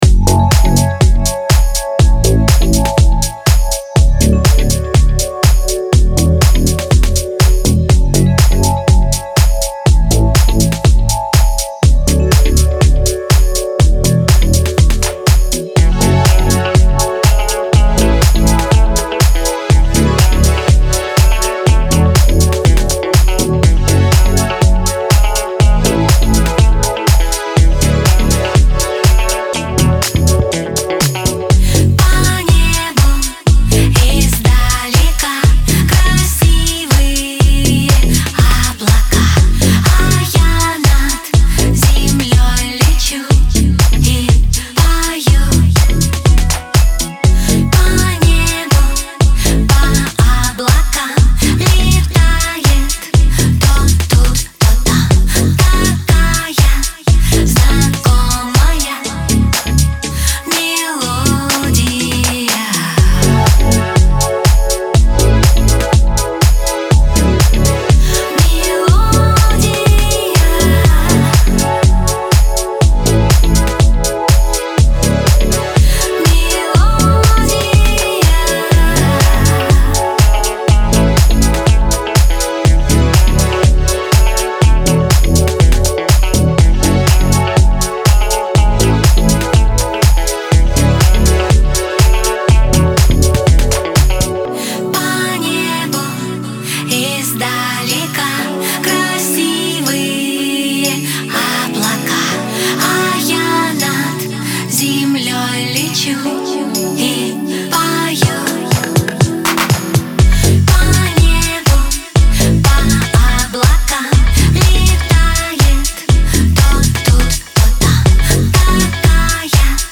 Жанр: Pop, Electronic, House
Стиль: House